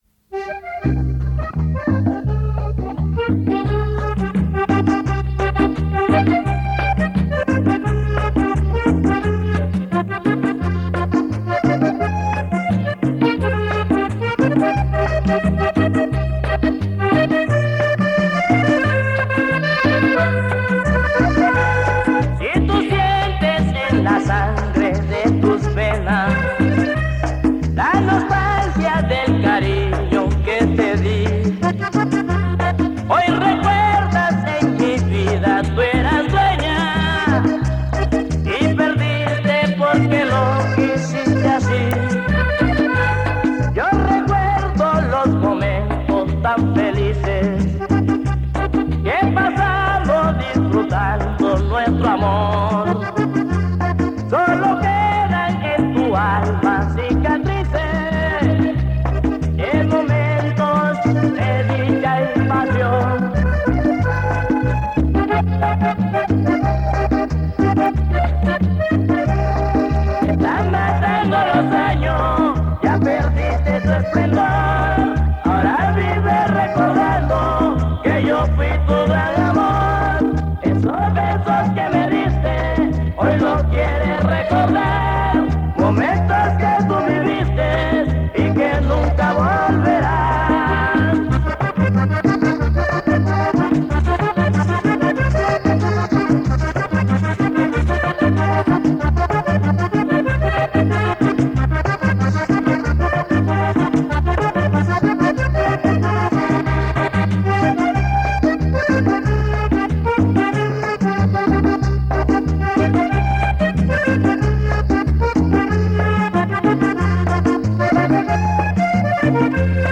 The songs are much older than 1992 so this must have been the year the tape was made or what was popular in that year. The cover design suggests that it is a bootleg rather than a homemade mixtape.